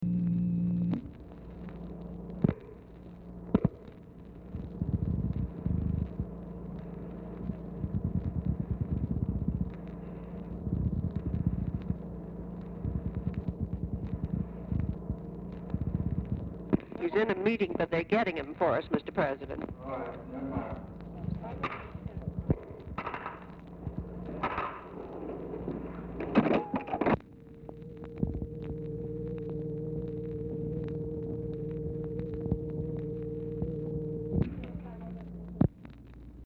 Telephone conversation # 3528, sound recording, LBJ and TELEPHONE OPERATOR, 5/27/1964, time unknown | Discover LBJ
Format Dictation belt
Specific Item Type Telephone conversation